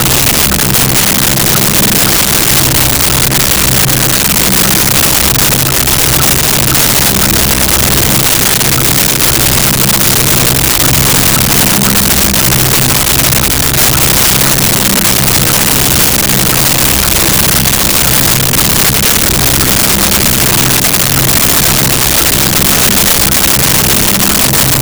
Clock
CLOCK.wav